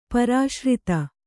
♪ parāśrita